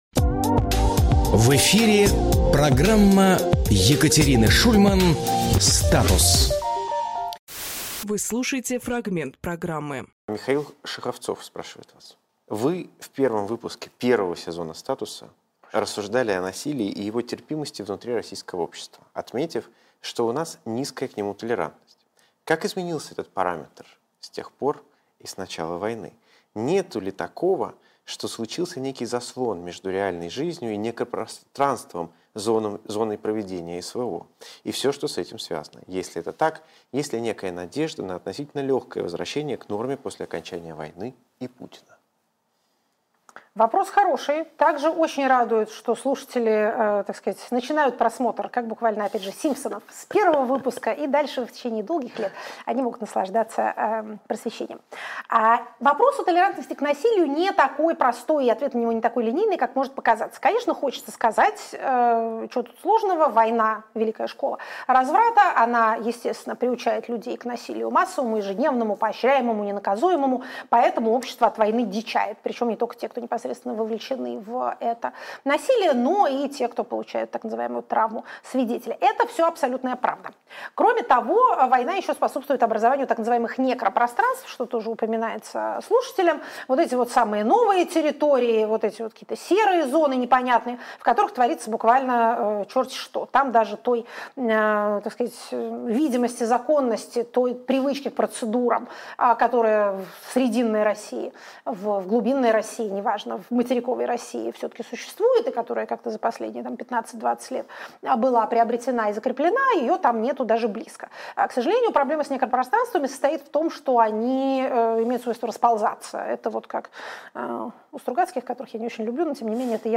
Екатерина Шульманполитолог
Фрагмент эфира от 03.03.2026